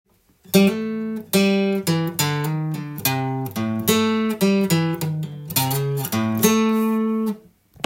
Aのブルースでも弾けるようにkeyを変換してみました.